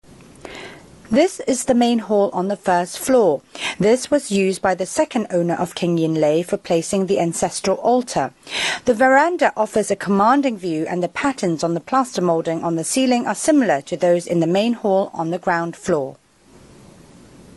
Vocal Description